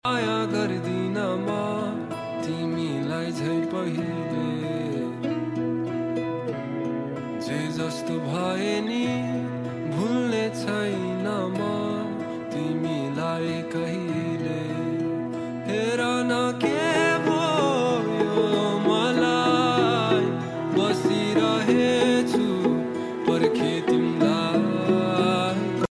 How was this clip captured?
Street festival